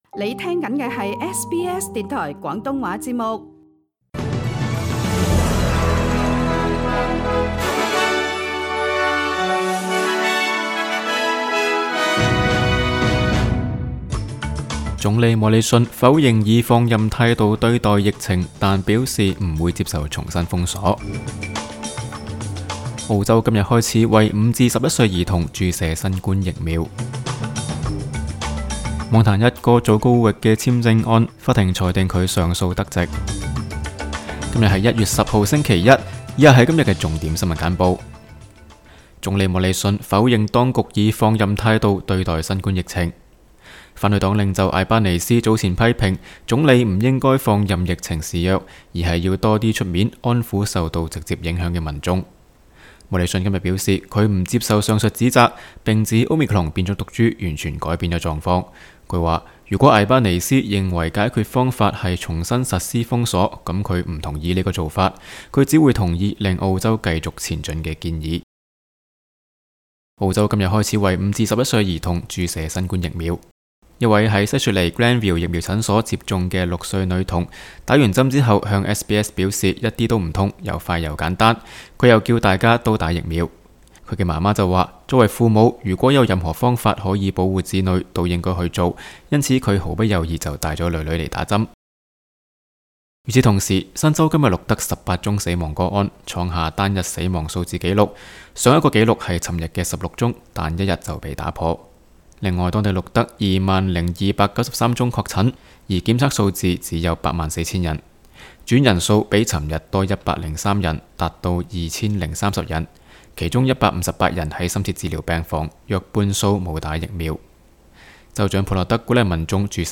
SBS 新聞簡報（1月10日）